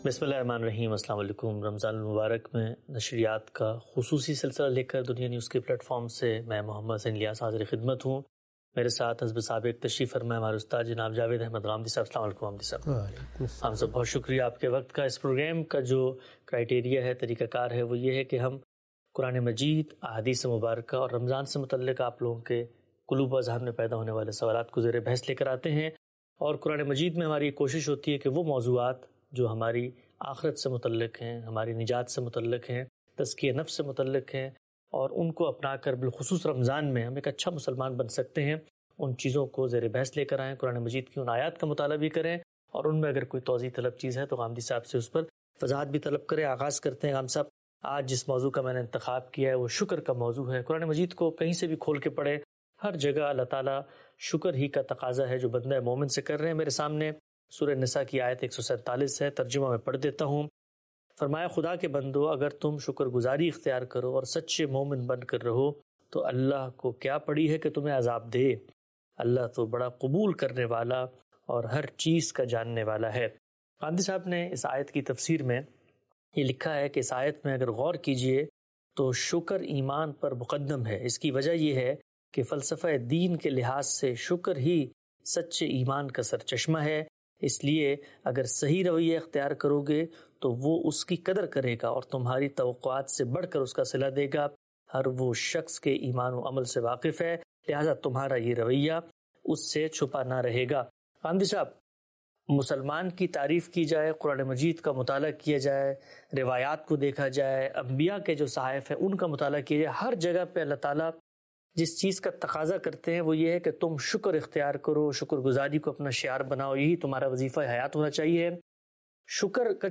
In this program Javed Ahmad Ghamidi answers the questions about "Gratitude".